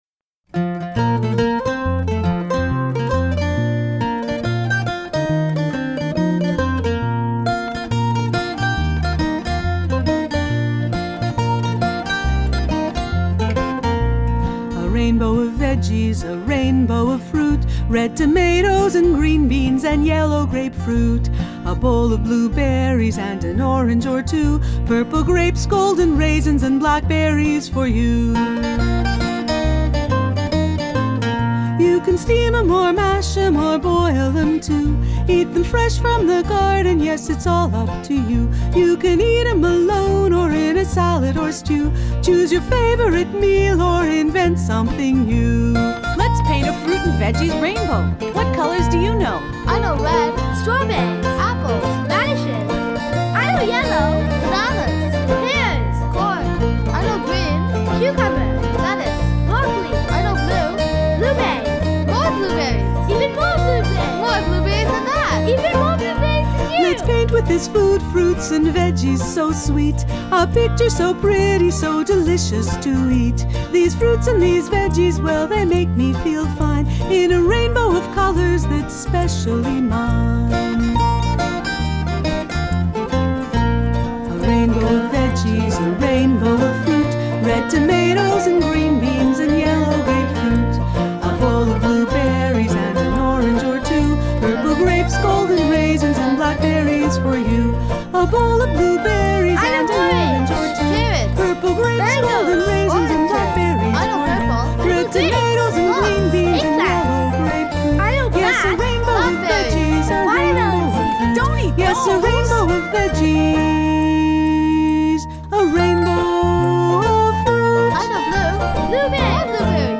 Studio recording